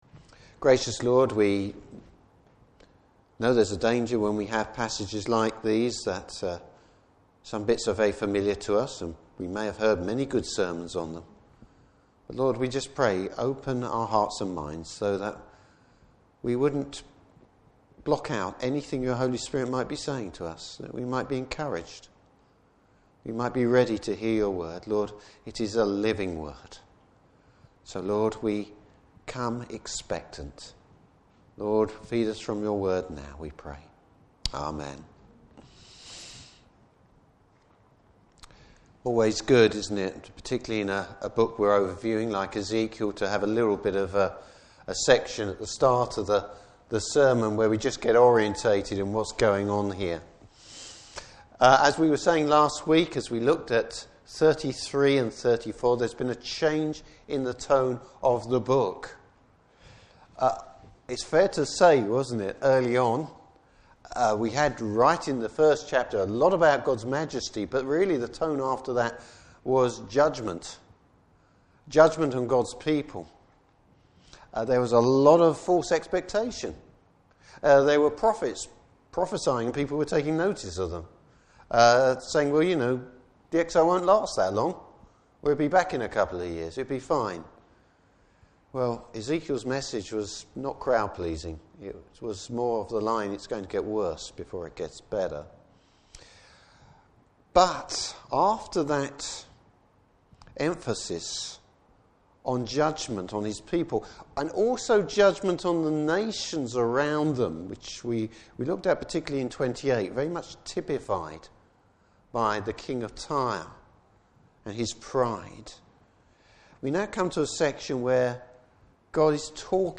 Service Type: Evening Service Bible Text: Ezekiel 37.